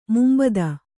♪ mumbada